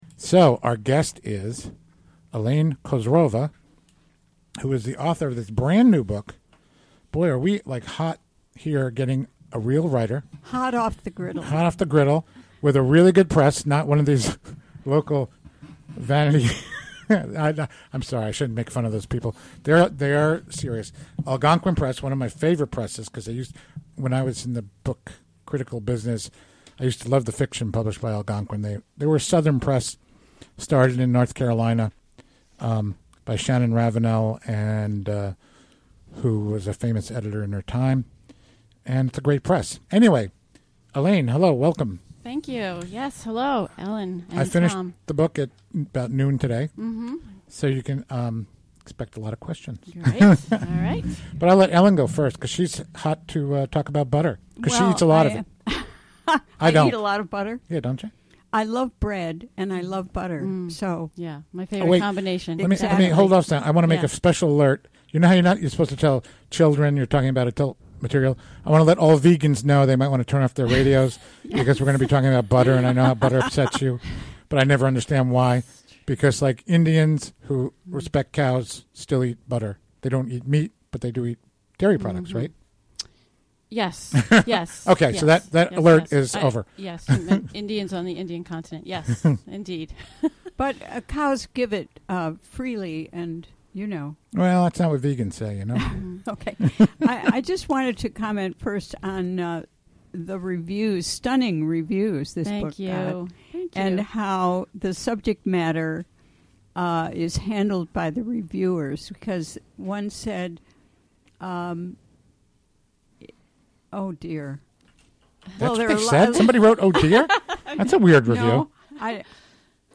Recorded during the WGXC Afternoon Show Thursday, January 5, 2017.